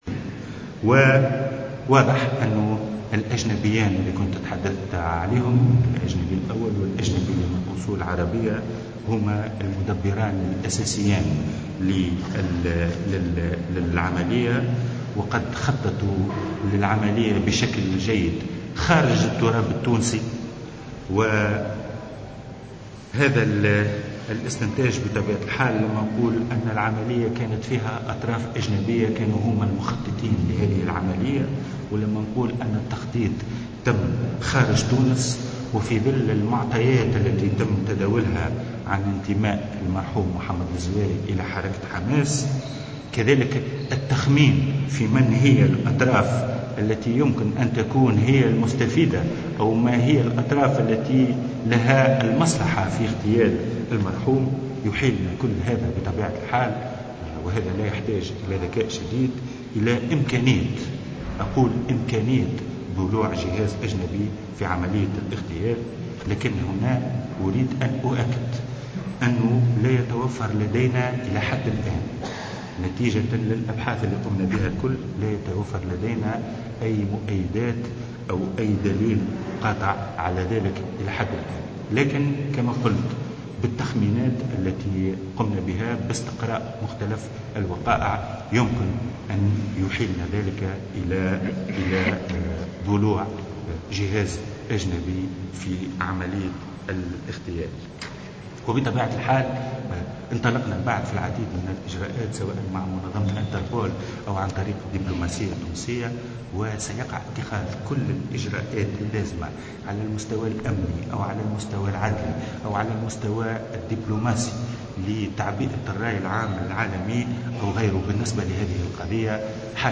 و أضاف الوزير خلال ندوة صحفية عقدها مساء اليوم أن المعطيات الأولية تشير إلى إمكانية تورط جهاز أجنبي في اغتيال المهندس "محمد الزواري".